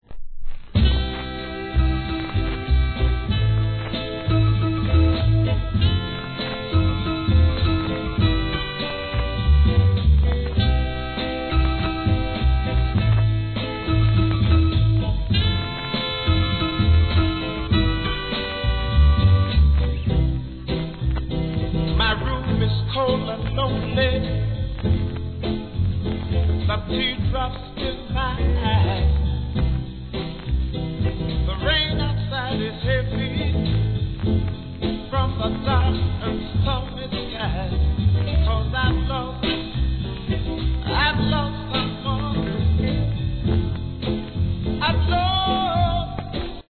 盤面に傷、スレ目立つためややノイズ入ります
REGGAE